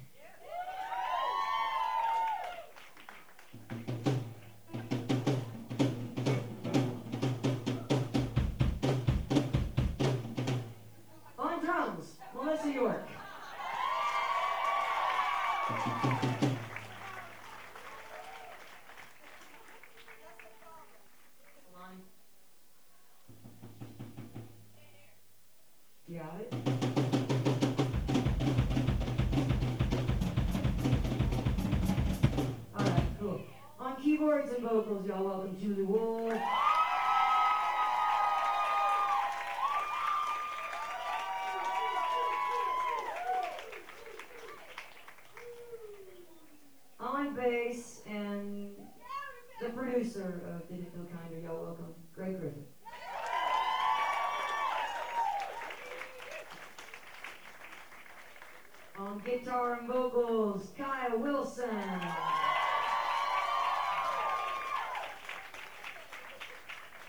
07. talking with the crowd (1:07)